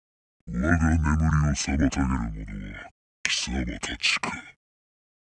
モンスターボイス（台詞）
モンスターボイス（唸り声）
マイミク申請（老人）